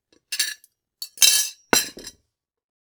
household
Cutlery and Crockery Noise